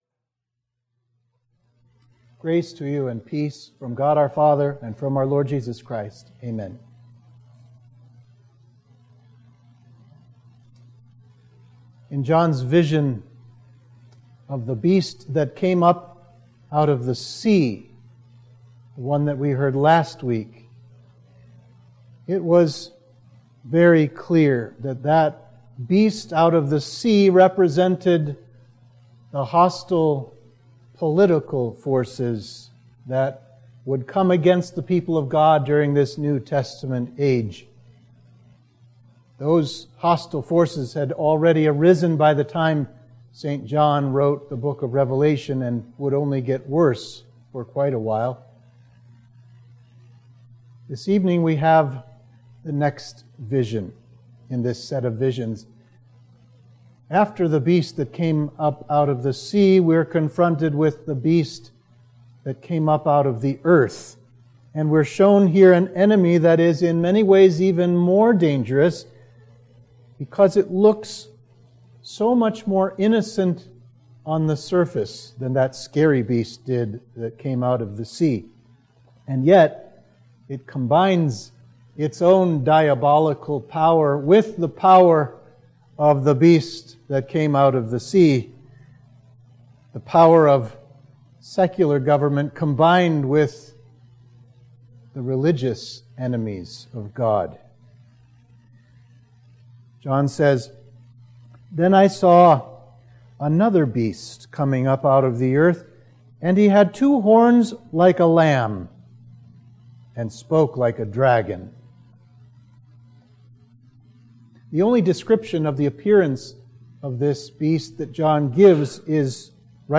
Sermon for Midweek of Trinity 6